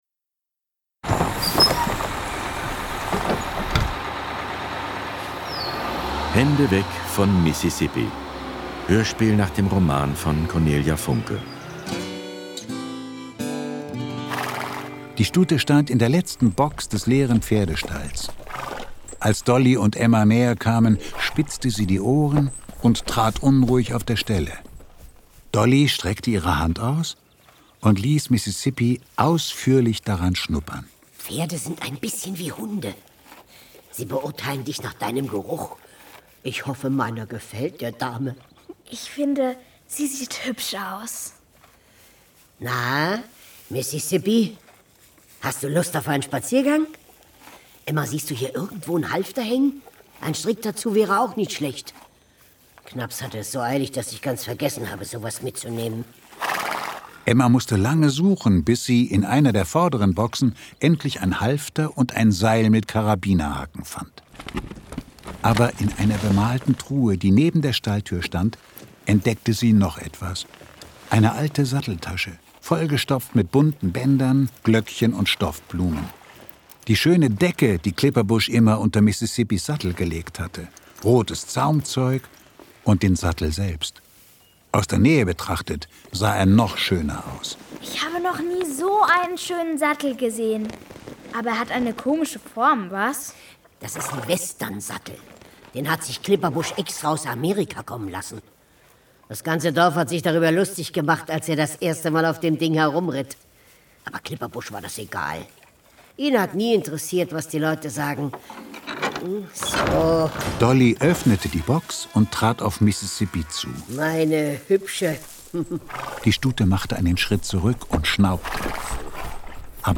Hände weg von Mississippi Das Hörspiel Cornelia Funke (Autor) Thorsten Zeitnitz (Komponist) Audio-CD 2020 | 2.